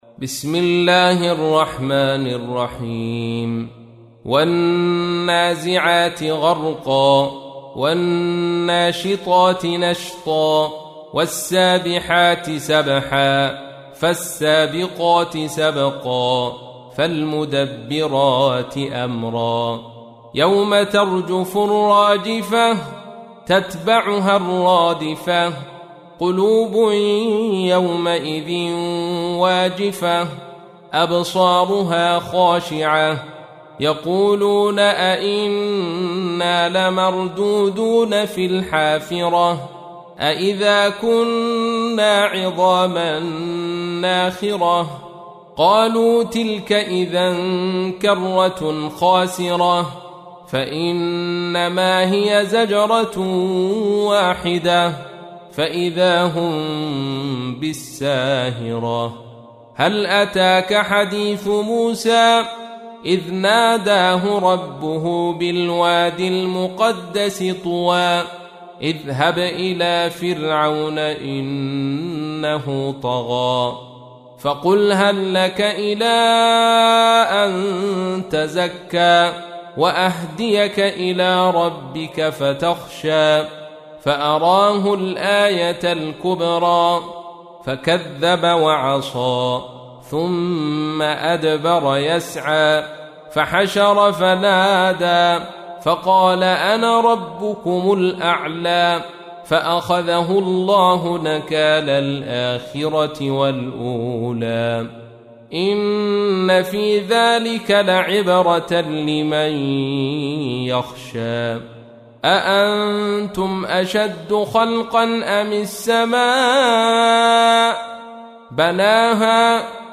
تحميل : 79. سورة النازعات / القارئ عبد الرشيد صوفي / القرآن الكريم / موقع يا حسين